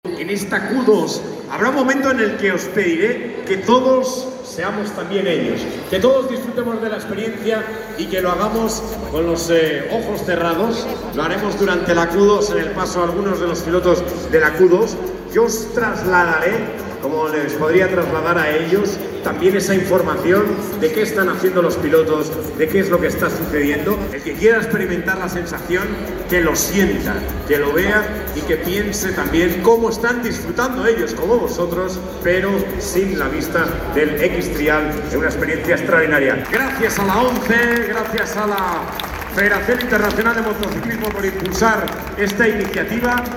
Gracias a la ONCE y a la FIM por impulsar esta iniciativa”, retumbaba entre aplausos en el Madrid Arena.
audio-speaker-del-evento